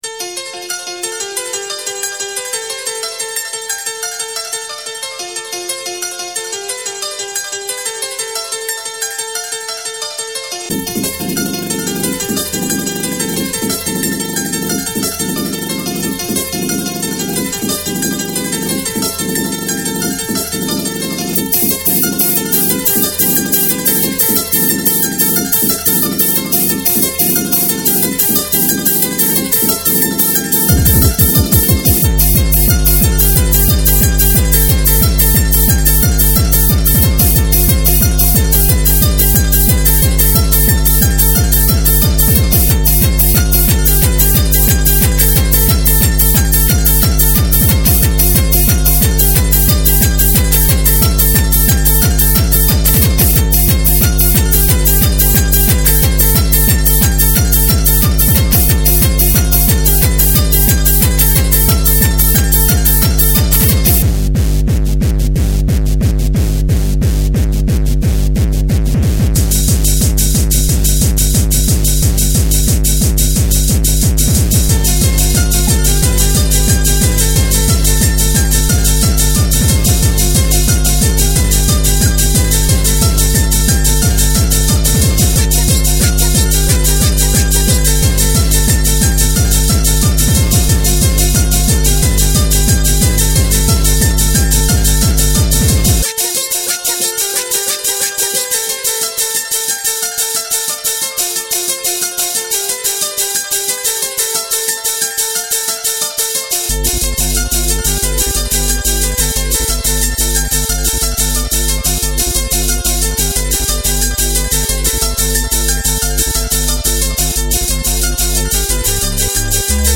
hardcore remix
• Jakość: 44kHz, Stereo